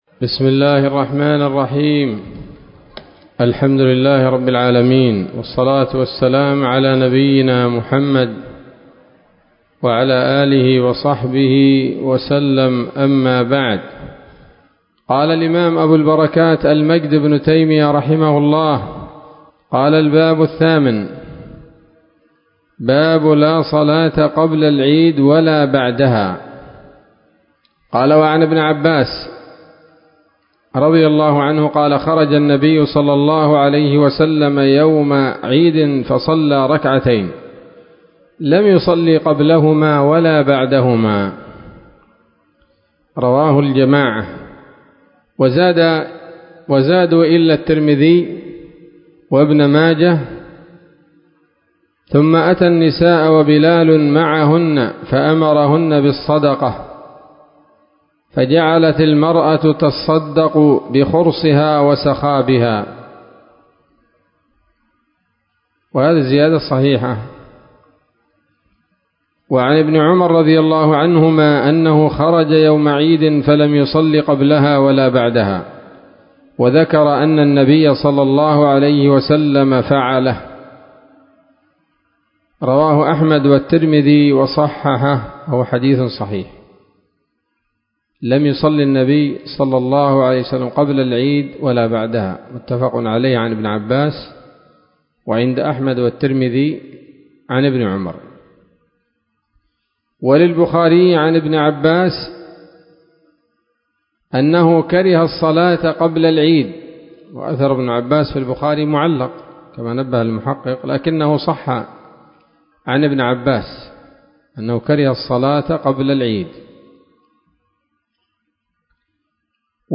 الدرس الثالث عشر من ‌‌‌‌كتاب العيدين من نيل الأوطار